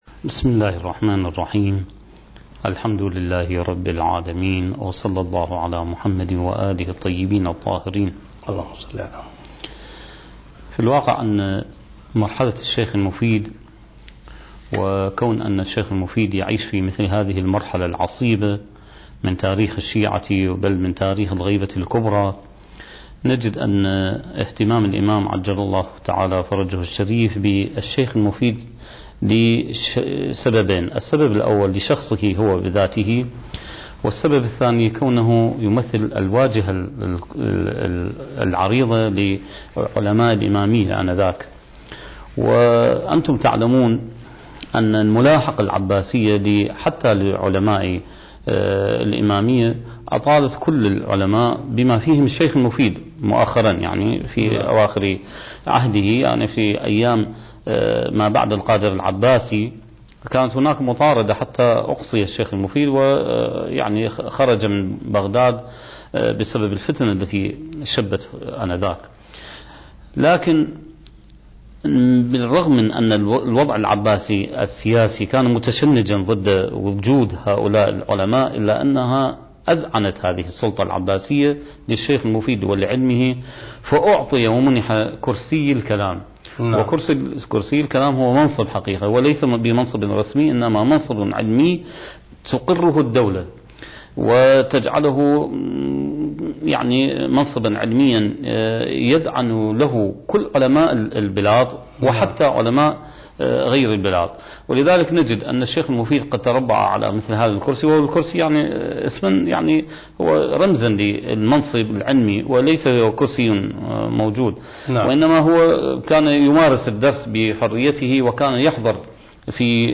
سلسلة محاضرات: بداية الغيبة الصغرى (7) برنامج المهدي وعد الله انتاج: قناة كربلاء الفضائية